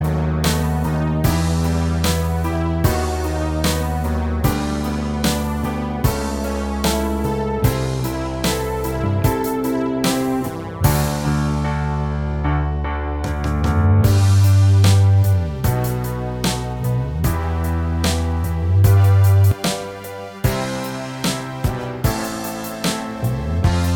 Minus Guitars Rock 4:15 Buy £1.50